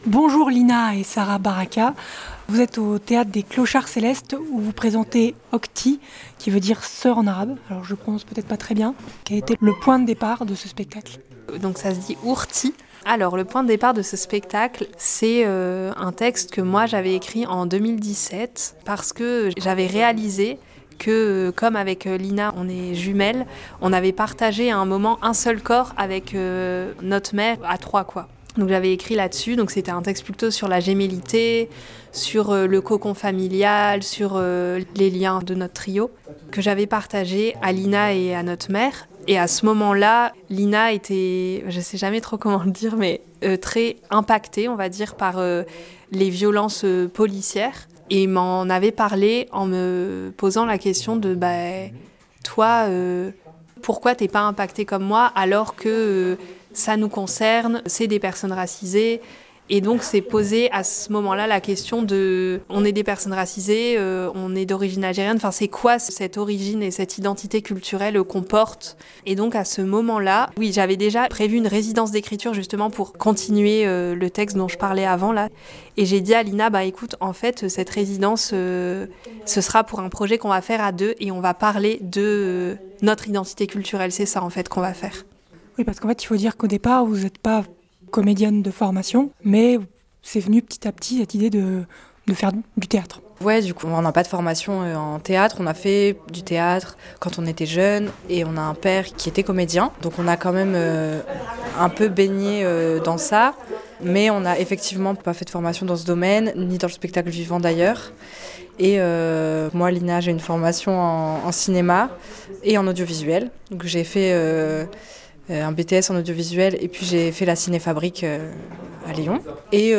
Entretien audio (12 min) avec les deux sœurs à l'issue de la représentation du samedi 15 mars 2025 au Théâtre des Clochards Célestes de Lyon.